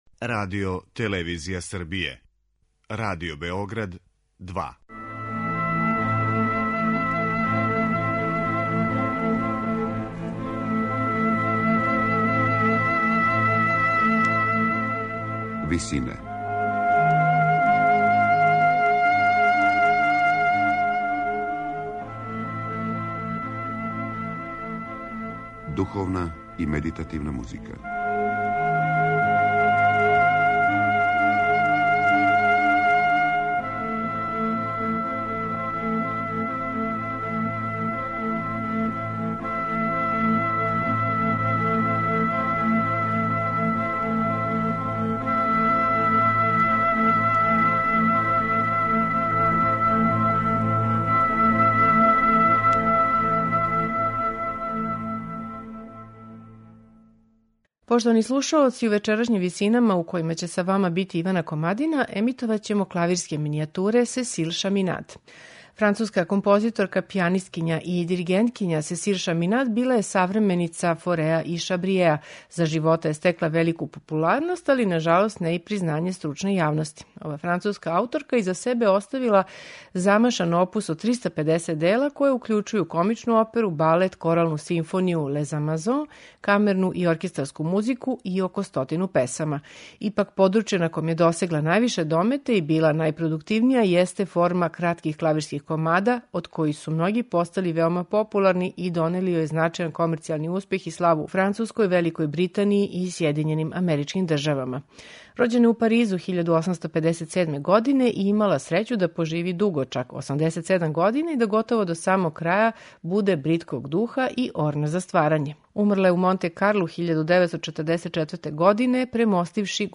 Клавирске минијатуре